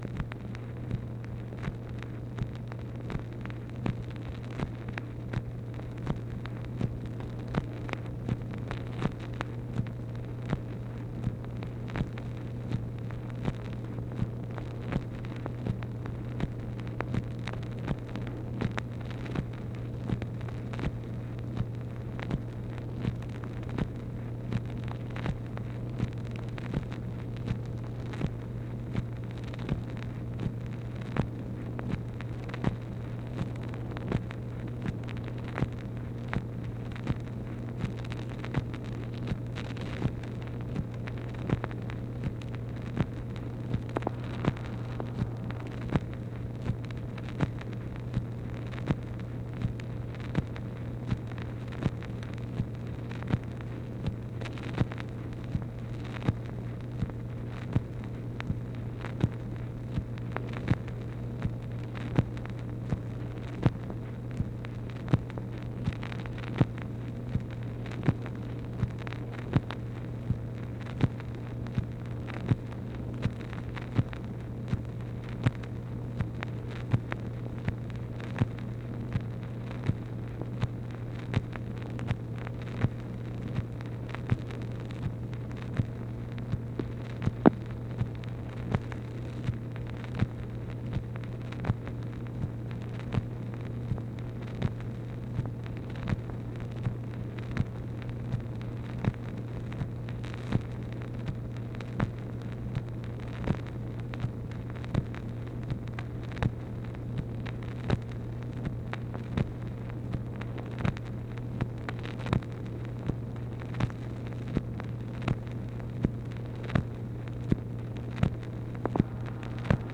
MACHINE NOISE, April 26, 1964